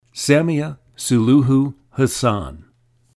HASSAN, SAMIA SULUHU SAM-ee-uh soo-LOO-hoo Ha-SAHN